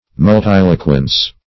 Search Result for " multiloquence" : The Collaborative International Dictionary of English v.0.48: Multiloquence \Mul*til"o*quence\, n. Quality of being multiloquent; use of many words; talkativeness.